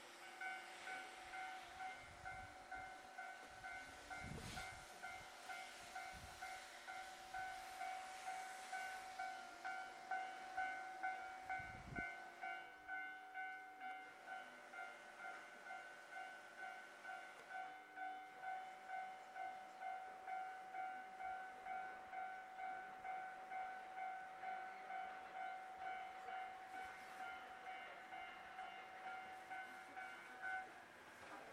この駅では接近放送が設置されています。
２番のりば日豊本線
接近放送普通　宮崎行き接近放送です。